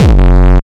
HD BD 14  -L.wav